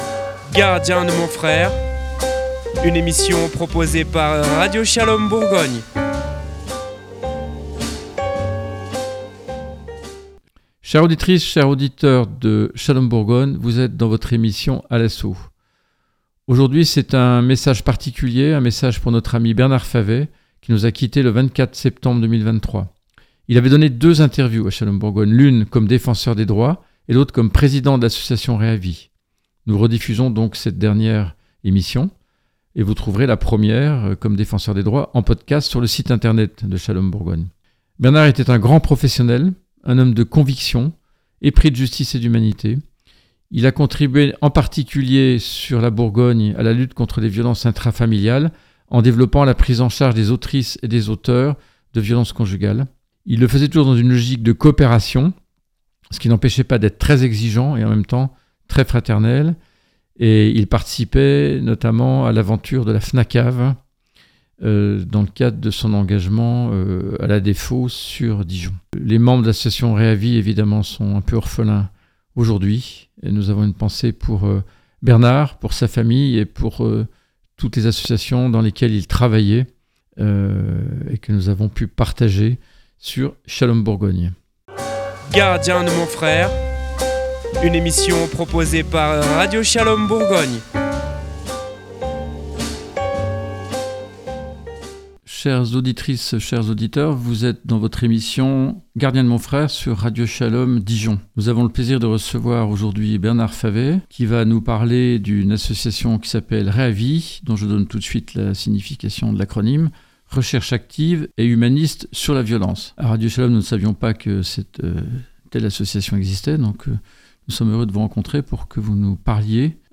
Nous rediffusons la dernière.